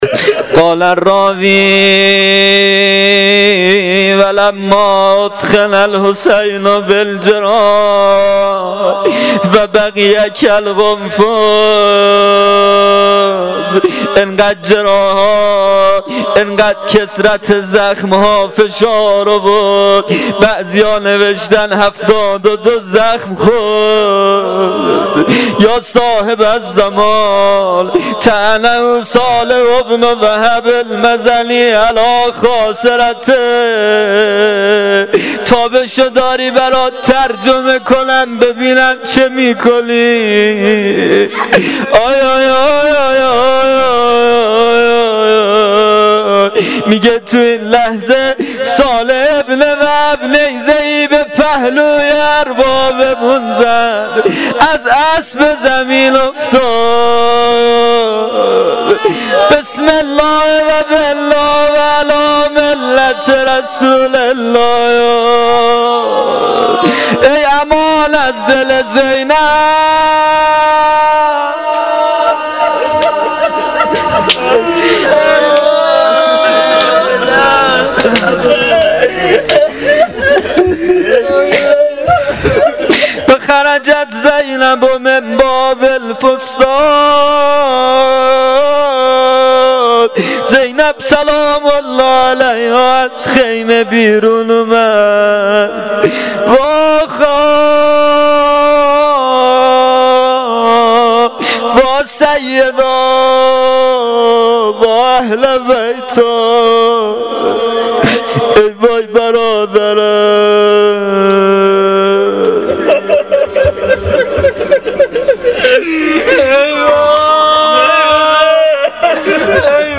مقتل خوانی شب دهم محرم الحرام 1396 (شب عاشورا)